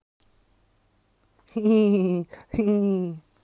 lachen2.wav